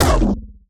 poly_explosion_dark01.wav